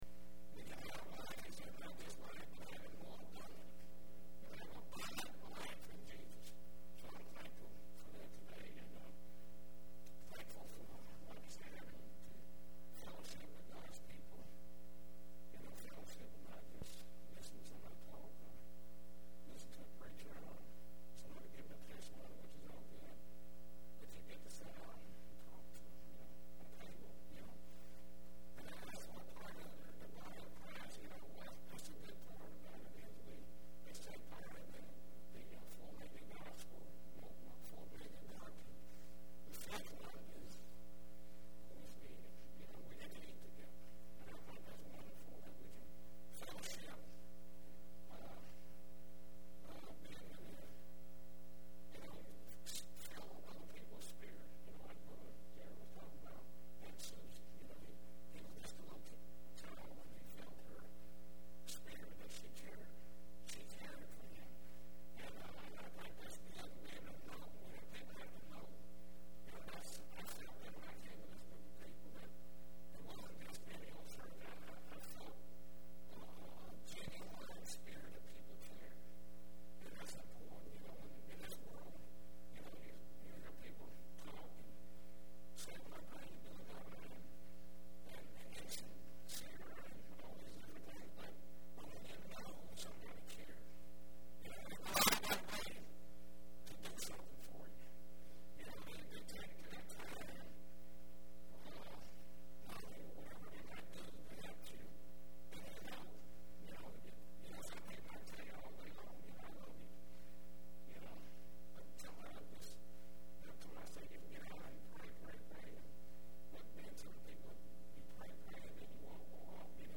5/23/10 Sunday Services